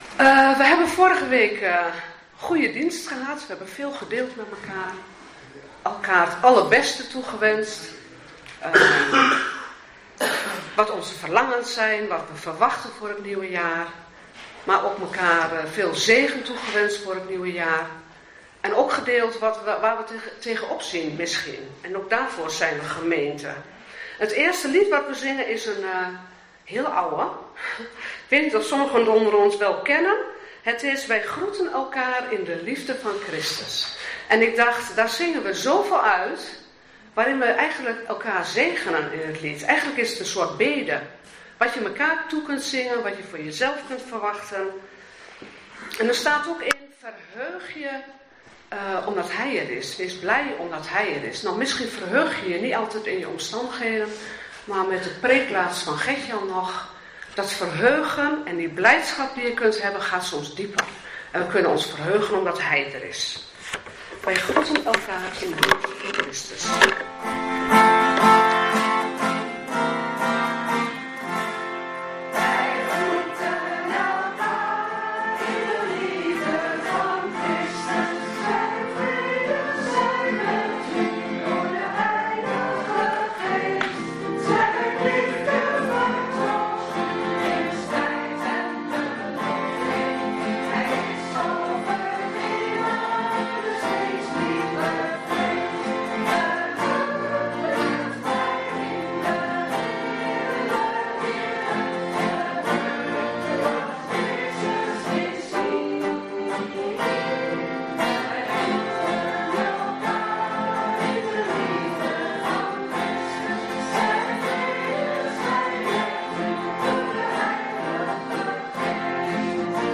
11 januari 2026 dienst - Volle Evangelie Gemeente Enschede